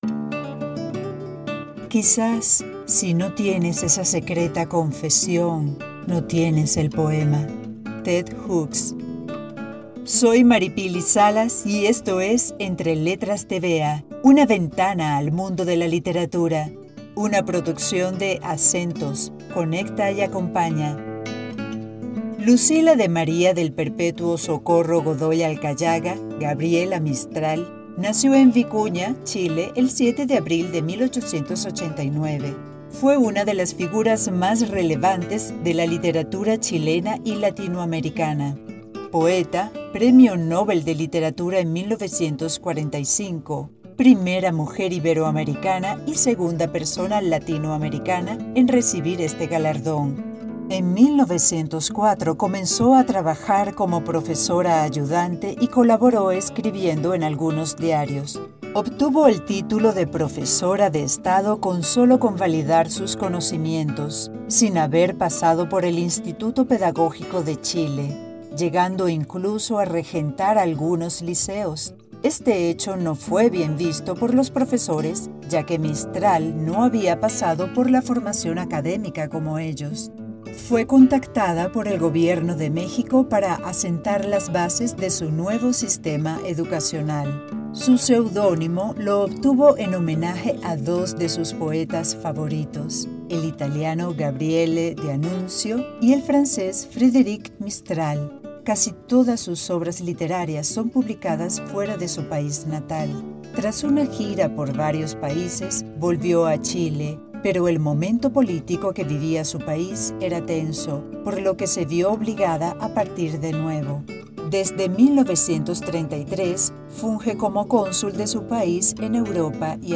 Narración y Texto